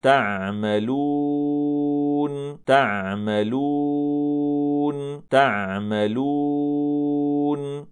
a ـــ Att inte cirkla om läpparna som krävs när man uttalar det, som i:
﴾تَعۡمَلُونَ﴿           Det ska uttalas på följande sätt: